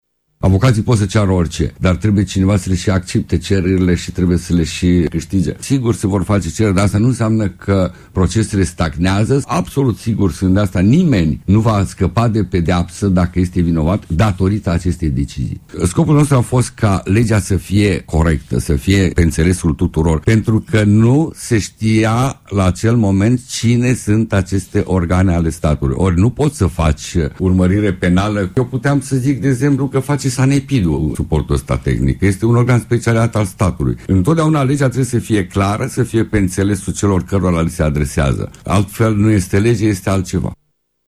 Augustin Zegrean a fost invitat la RRA să explice mai detaliat decizia Curţii cu privire la interceptări, dar şi implicaţiile acesteia. El a atras atenţia că hotărârile judecătoreşti definitive nu mai pot fi atacate, iar excepţiile de neconstituţionalitate pe care le vor ridica avocaţii în procesele în curs nu au cum să influenţeze mersul acestora: